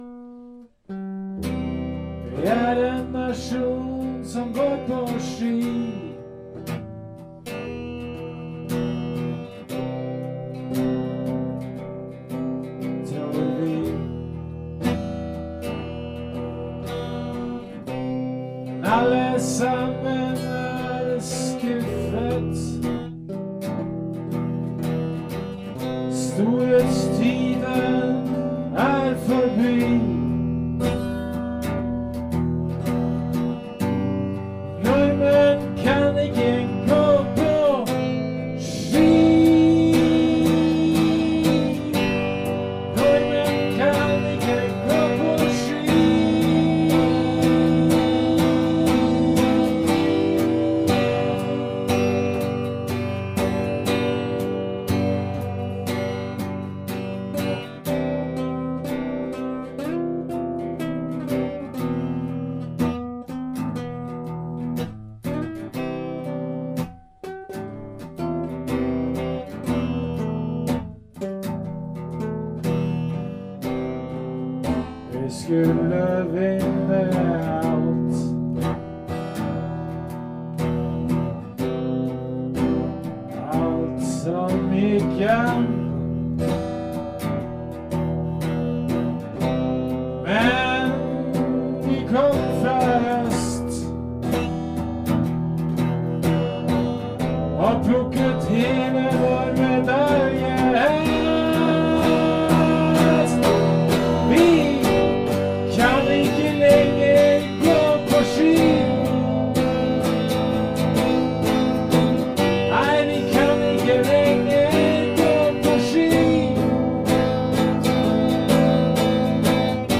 På denna jammingen spelade vi elgitarr bägge två.